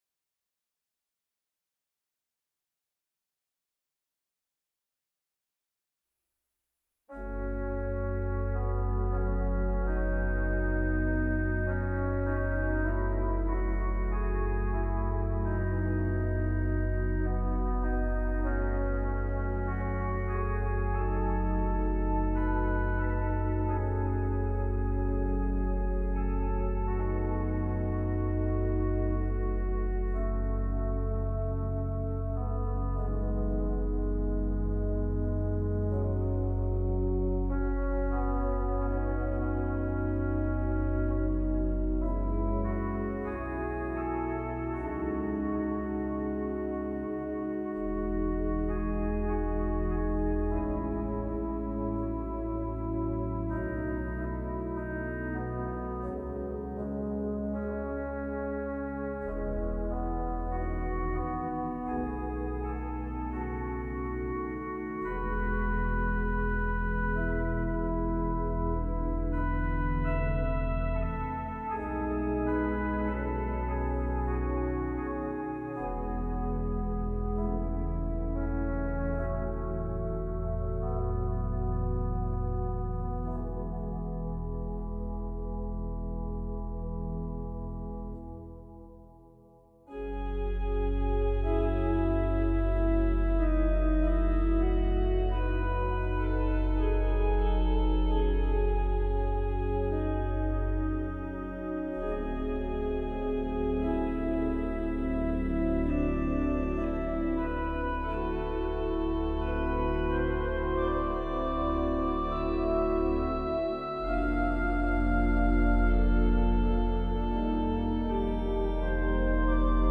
Peaceful works for quiet reflection